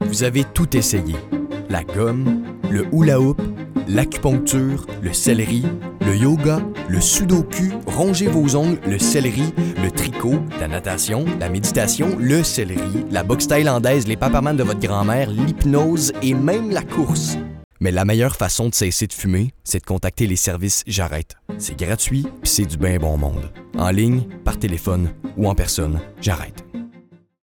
Démo voix